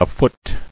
[a·foot]